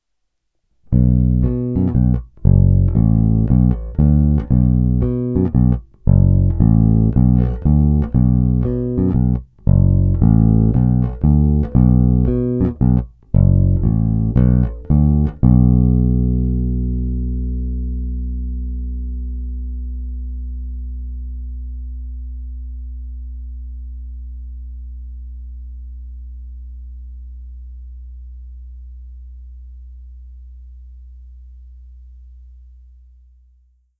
Je hutný, vrčivý, zvonivý, s bohatými středy, s příjemnými výškami a masívními basy.
Není-li uvedeno jinak, následující ukázky jsou pořízeny rovnou do vstupu zvukové karty a kromě normalizace ponechány bez jakéhokoli postprocesingu.
Hra nad snímačem